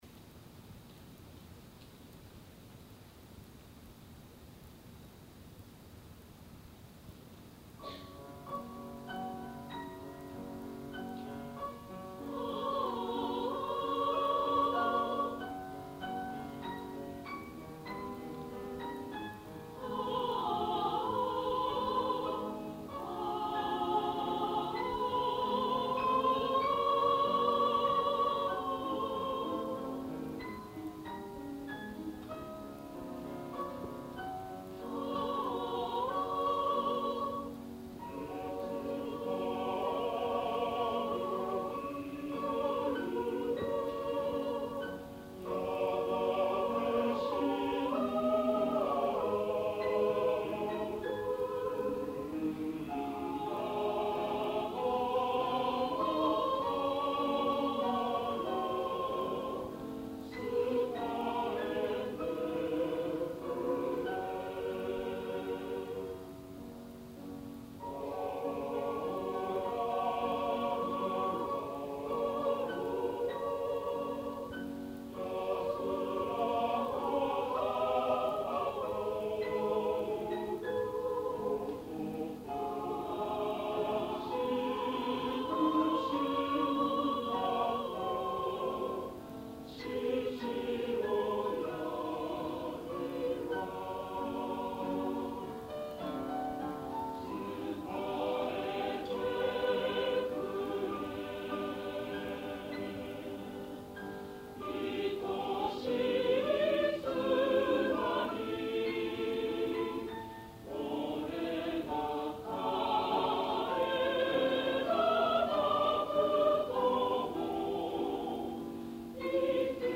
１９９９年６月１３日　東京・中野ゼロホールで
ソプラノ５名、アルト５名、テナー４名、ベース３名の
編成です。男性１名カウンターテナーがアルトです。
お母様が小さなラジカセでテープ録音をして頂き、
音量、音質はご勘弁下さい。
山男の歌、とても感動的なハーモニーです
合唱団　某大学ＯＢ会合唱団メンバー　１７名